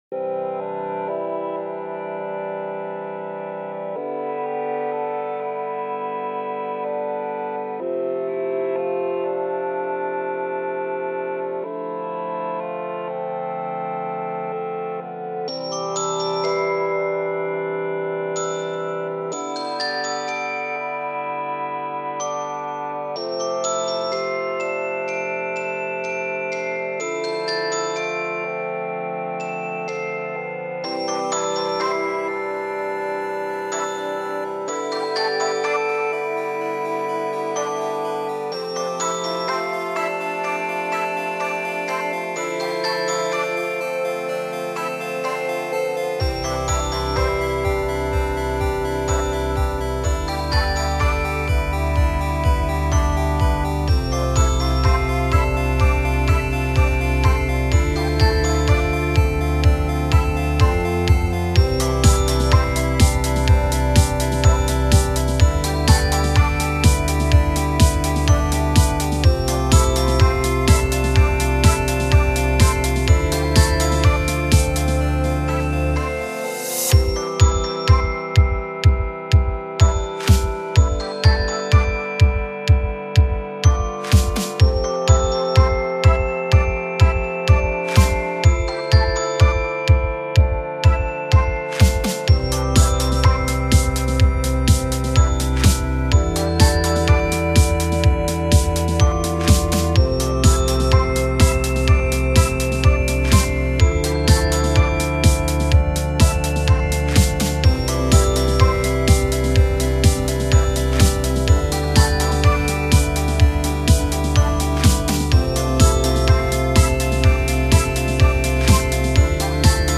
Some really cheesy applejack music, enjoy